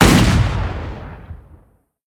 bighandgun_shot_a.ogg